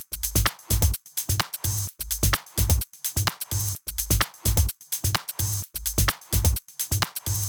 VFH1 128BPM Moonpatrol Kit 7.wav